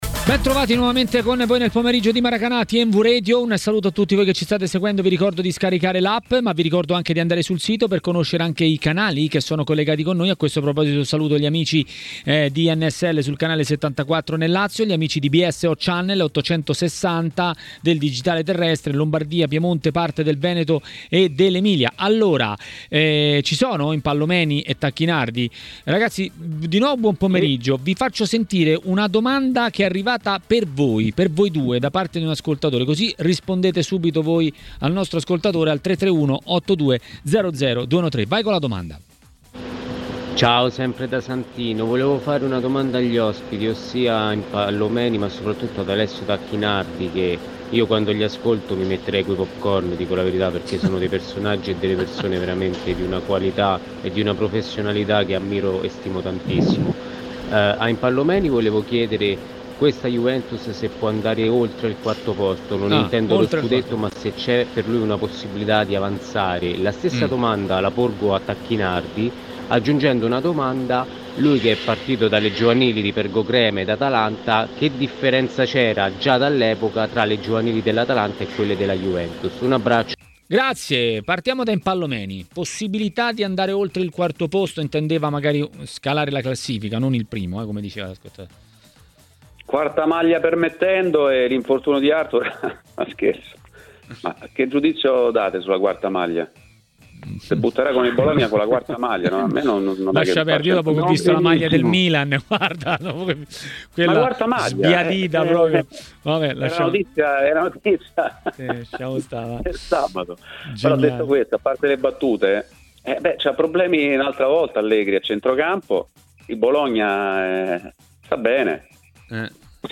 A Maracanà, nel pomeriggio di TMW Radio, ha parlato delle italiane nelle coppe e non solo l'ex calciatore e tecnico Alessio Tacchinardi